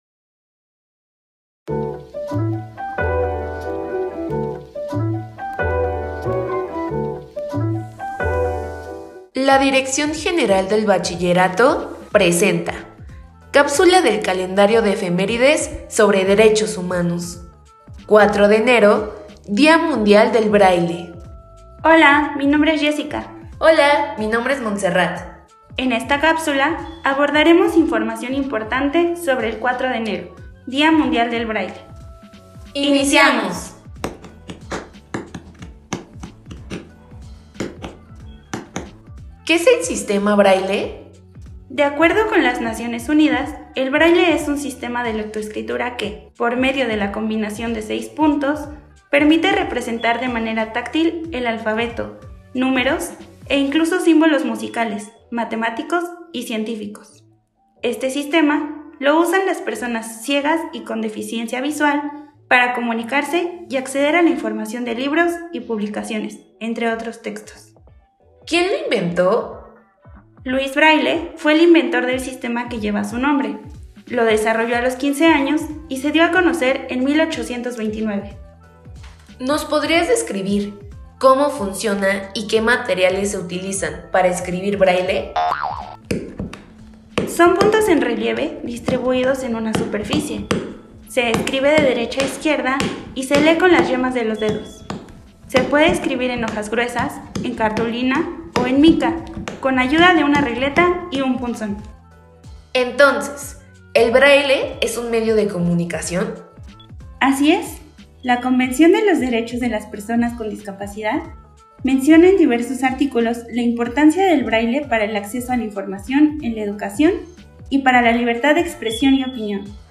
Cápsula de audio informativa